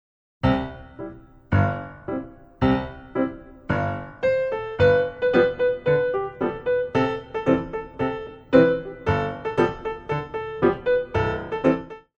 Grand Battement